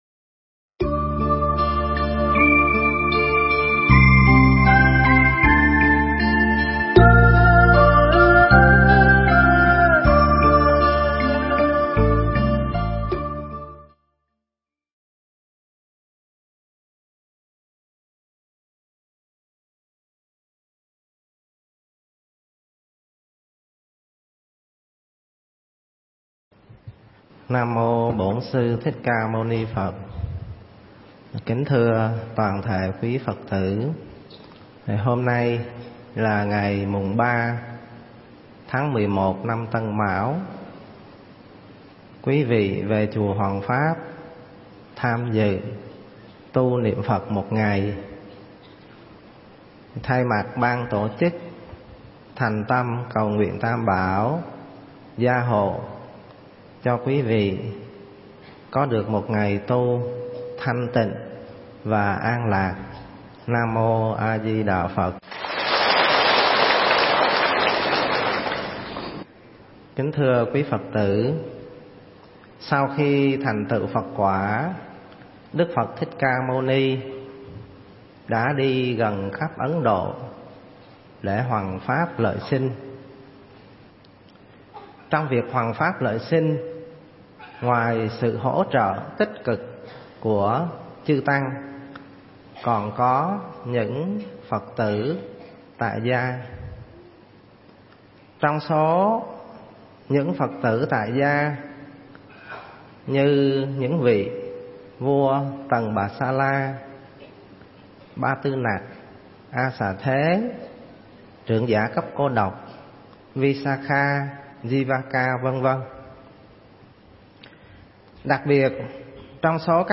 Nghe Mp3 thuyết pháp Cư Sĩ Cấp Cô Độc